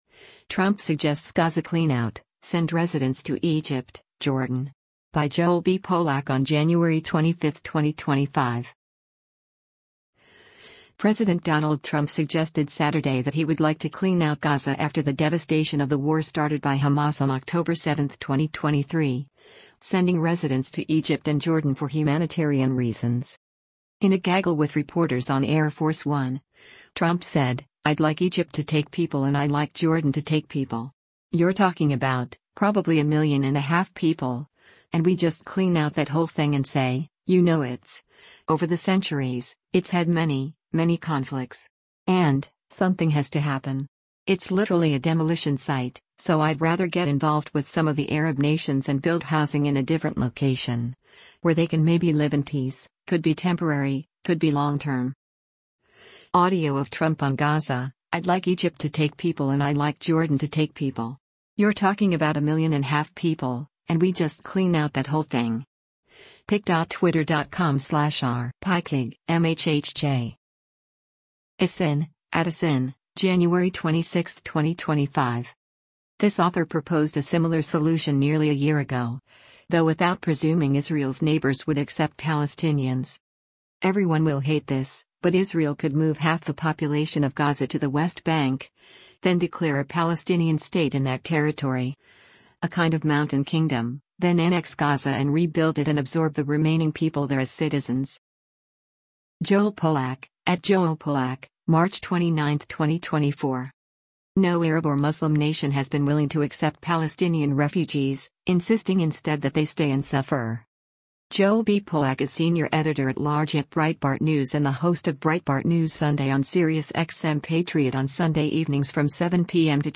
President Donald Trump speaks to reporters aboard Air Force One as he travels from Las Veg